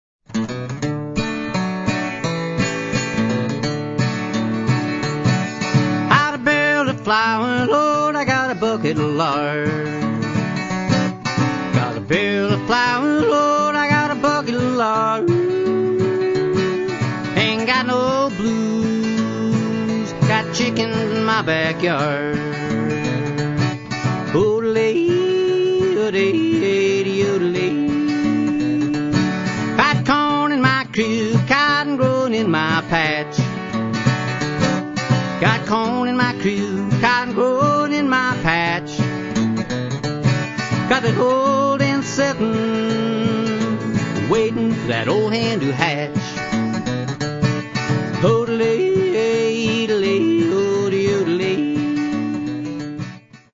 guitar
a definite old-time country feel to it